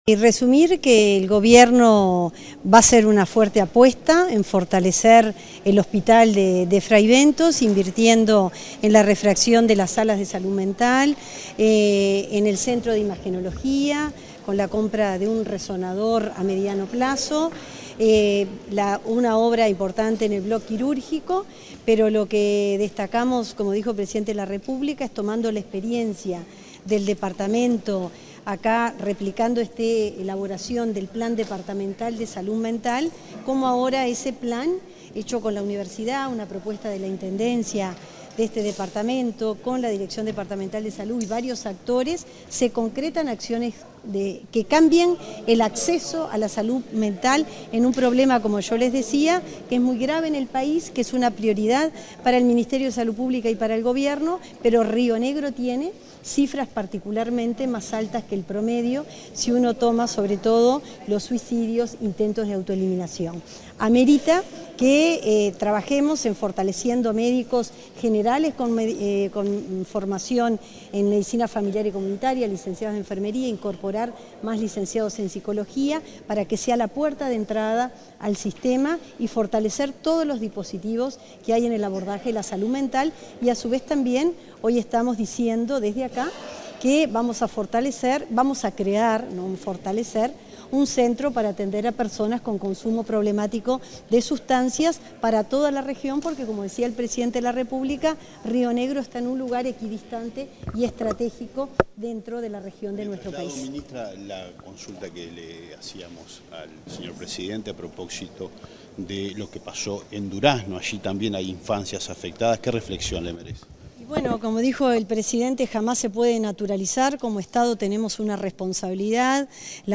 Declaraciones de la ministra de Salud Pública, Cristina Lustemberg
Declaraciones de la ministra de Salud Pública, Cristina Lustemberg 06/05/2025 Compartir Facebook X Copiar enlace WhatsApp LinkedIn La ministra de Salud Pública, Cristina Lustemberg, dialogó con la prensa, luego de participar del lanzamiento del Plan de Salud Mental, realizado este martes 6 en Fray Bentos, departamento de Río Negro.